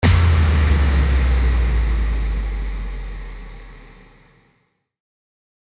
FX-1304-IMPACT
FX-1304-IMPACT.mp3